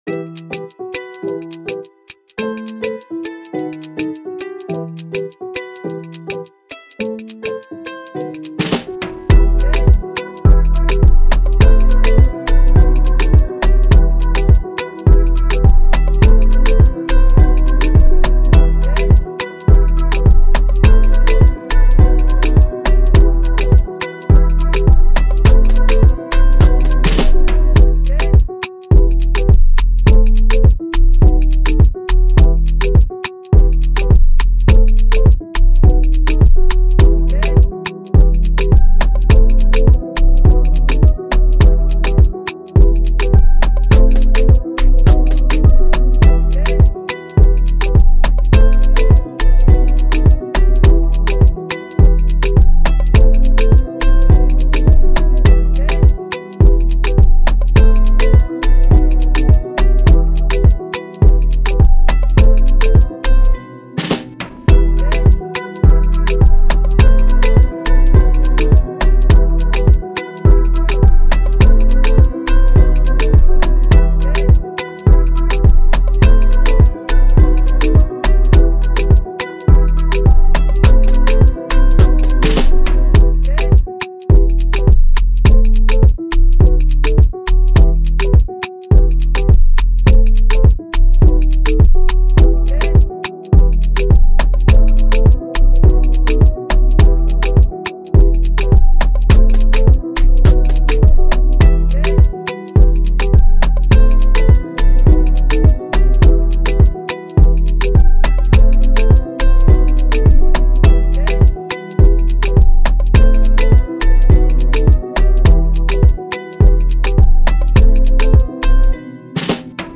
Afrobeat, Dance
E min